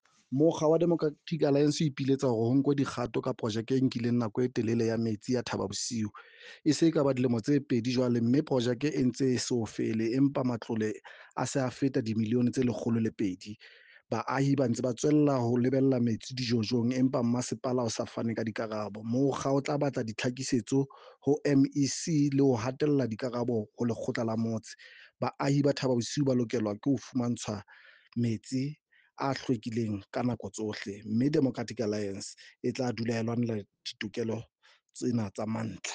Sesotho soundbites by Cllr Paseka Mokoena and